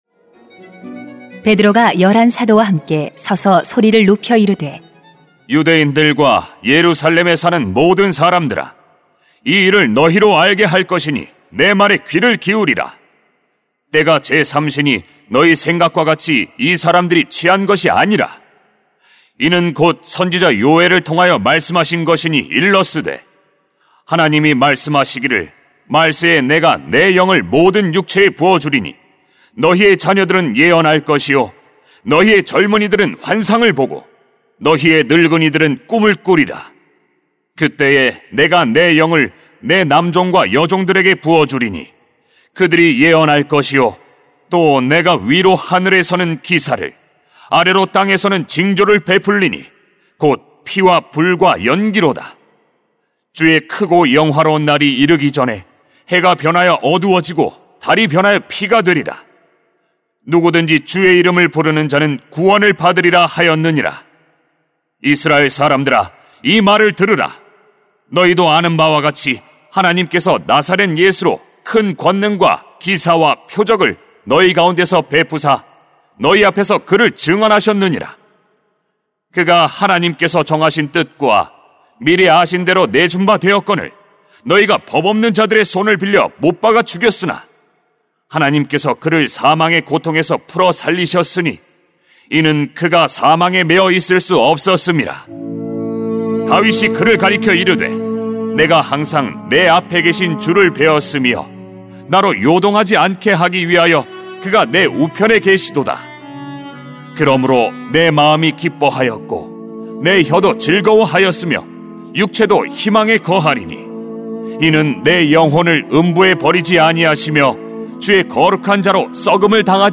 [행 2:14-36] 말씀의 성취 > 새벽기도회 | 전주제자교회